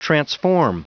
Prononciation du mot transform en anglais (fichier audio)